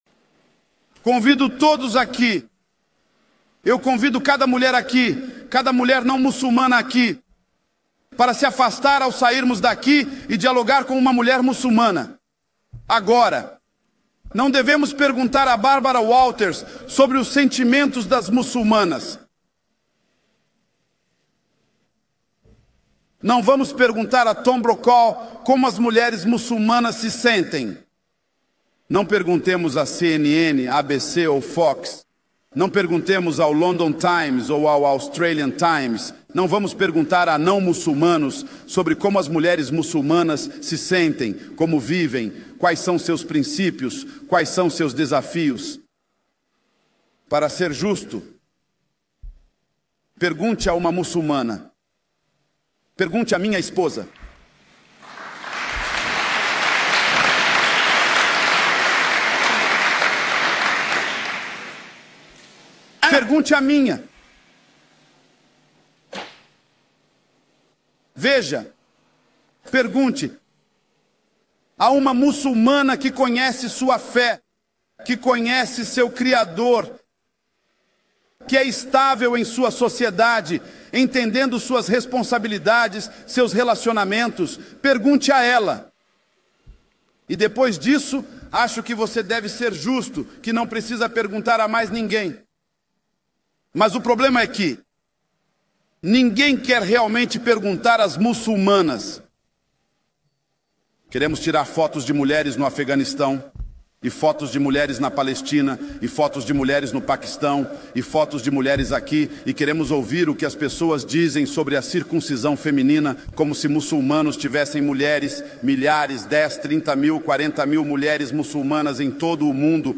O vídeo é um belo trecho de uma das palestras